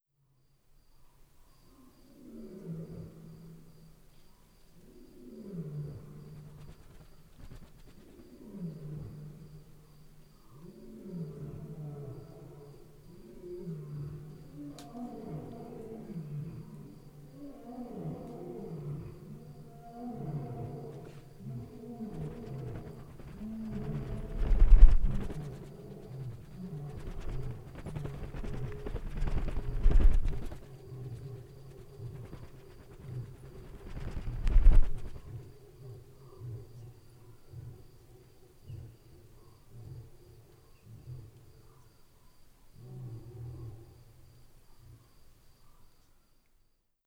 • lions and insect-hunting bats flapping wings.wav
lions_and_insect-hunting_bats_flapping_wings_FQz.wav